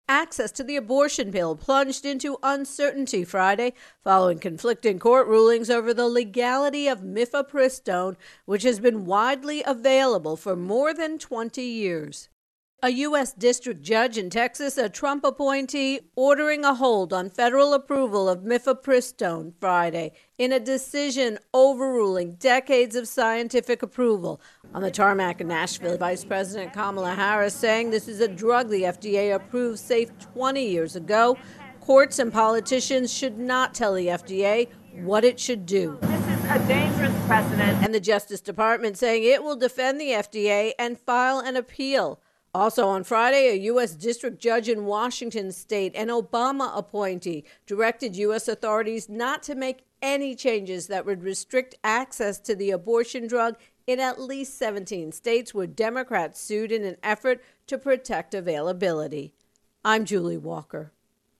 reports on Abortion Pills